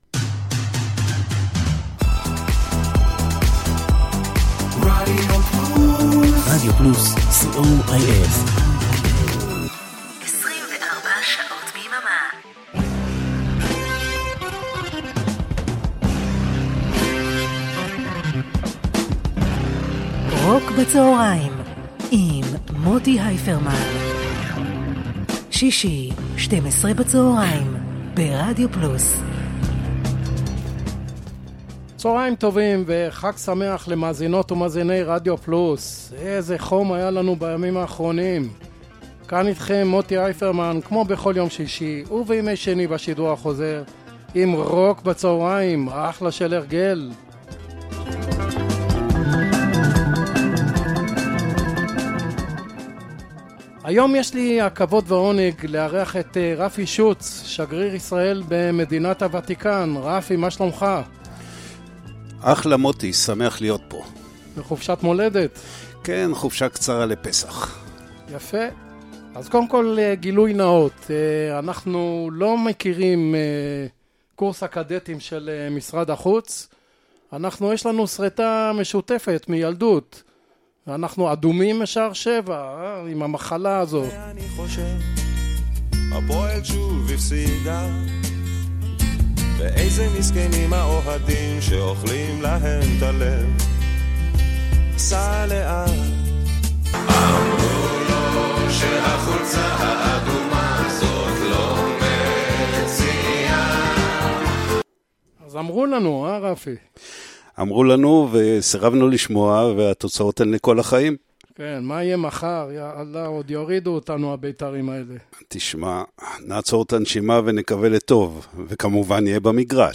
classic rock israeli rock pop rock